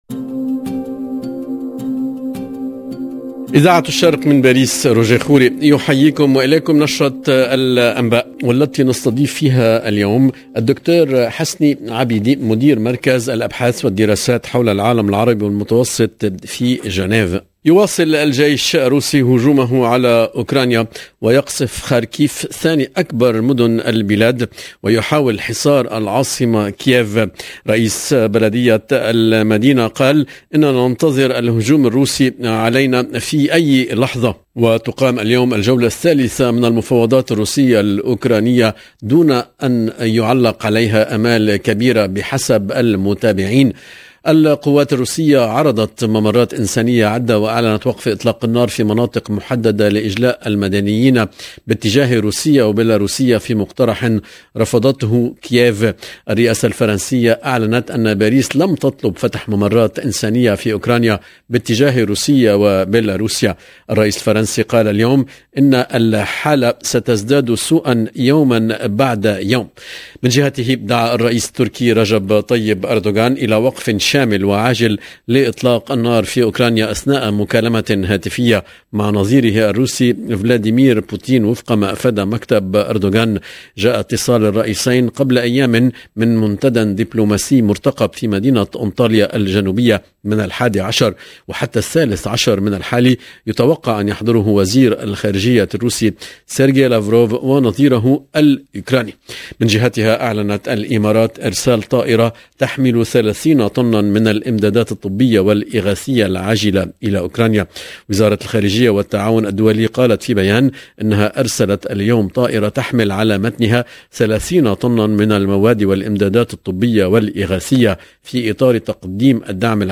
LE JOURNAL DU SOIR EN LANGUE ARABE DU 7/03/22